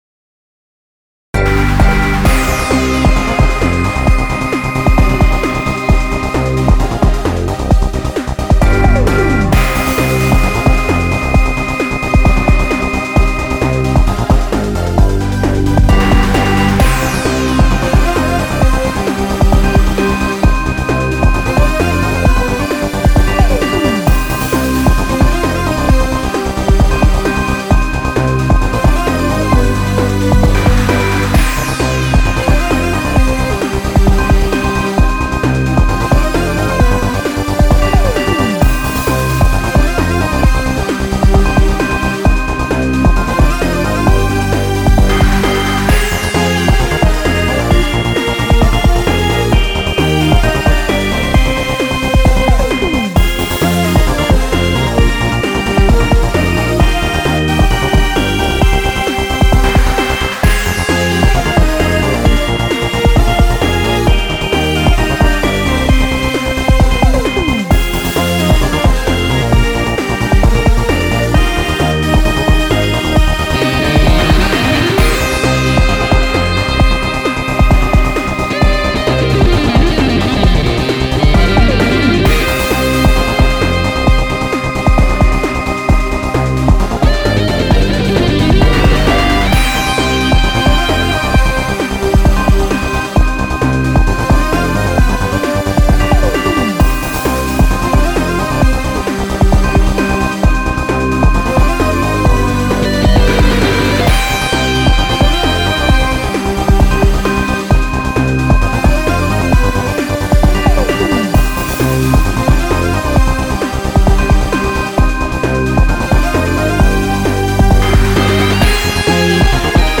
アップテンポエレクトロニカ激しい
BGM